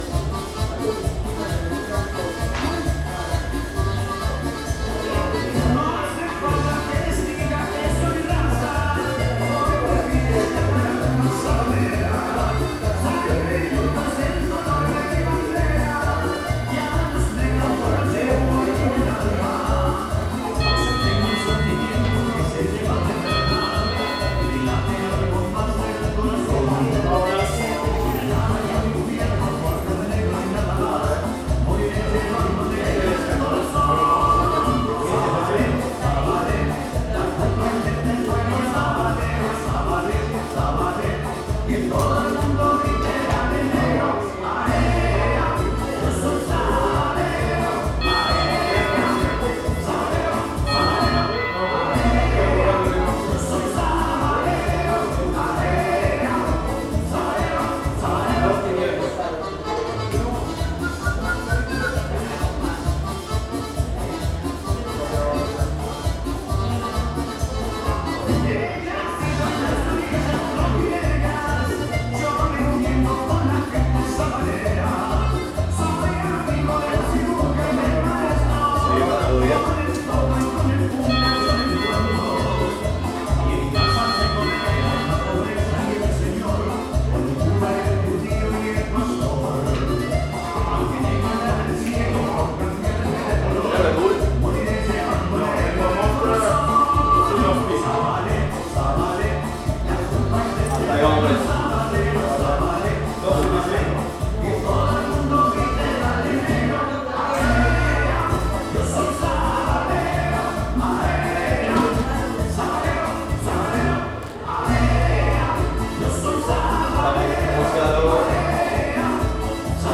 psr-picoteo-rosario-bar.mp3